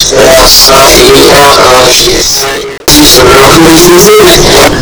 1) Distanza di 10,50 metri fra televisione e registratore.
2) Televisione anche su canale nazionale, ma lingua inglese.
4) Volume del registratore al max , e del televisore alto, abbastanza elevato